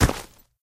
sounds / material / human / step / default1.ogg